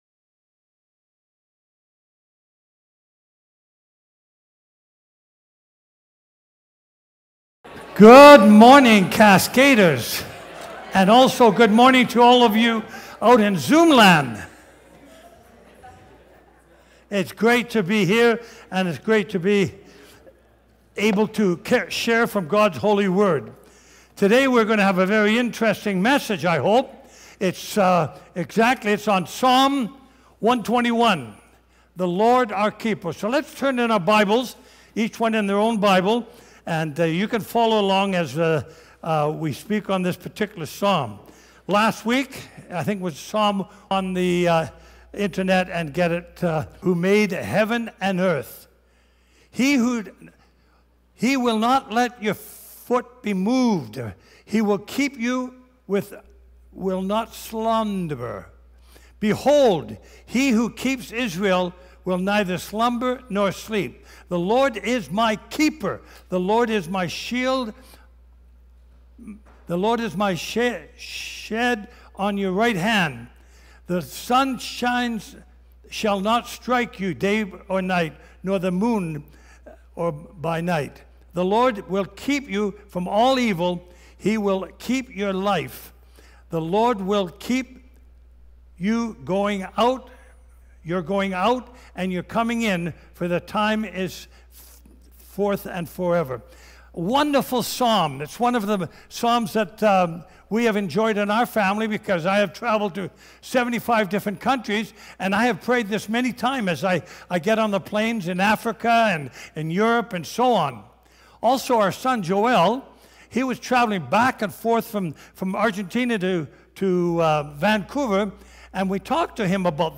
Cascades Church Sermons Psalm 121: The Lord, Our Keeper Play Episode Pause Episode Mute/Unmute Episode Rewind 10 Seconds 1x Fast Forward 30 seconds 00:00 / 19:27 Subscribe Share Apple Podcasts RSS Feed Share Link Embed